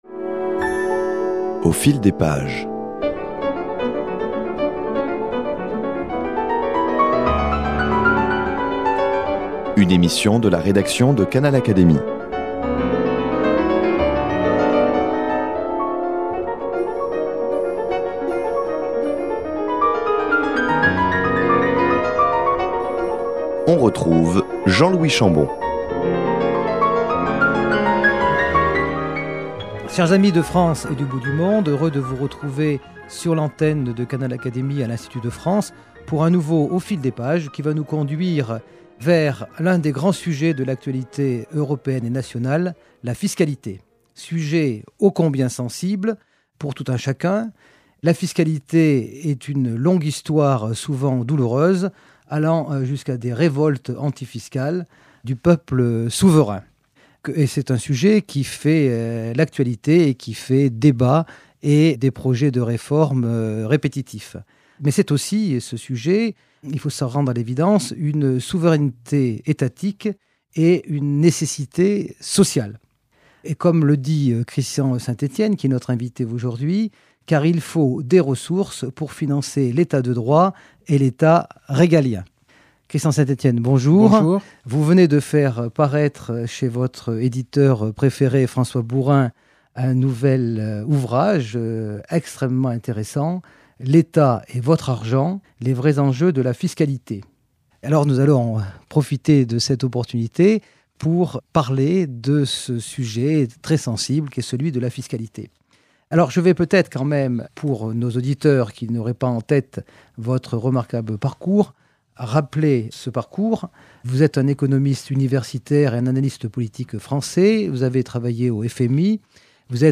Christian Saint-Étienne, économiste, analyste politique, et auteur de l’essai La fin de l’euro, présente ici son nouvel ouvrage : L’Etat et votre argent, une critique constructive de la fiscalité française. Il expose, dans un langage clair, les grands enjeux et débats de notre système d’imposition.